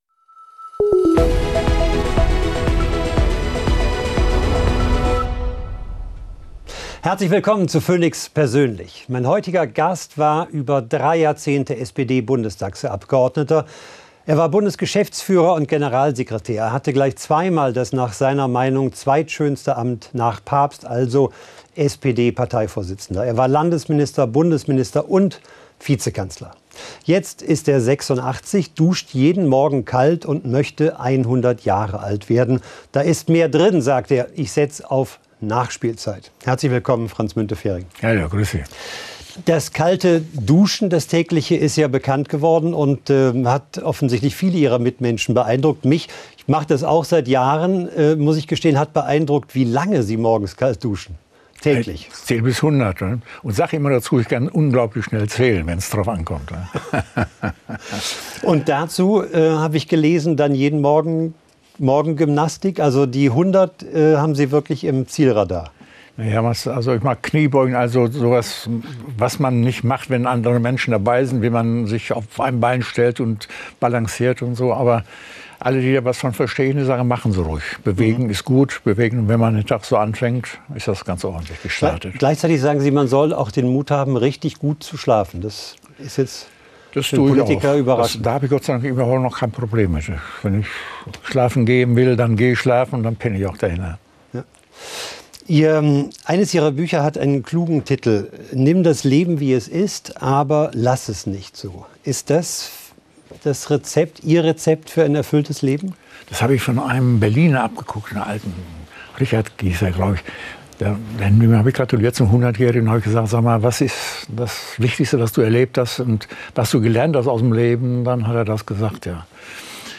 Theo Koll spricht mit dem früheren SPD-Chef Franz Müntefering über den Zustand der SPD und die Zukunft Europas.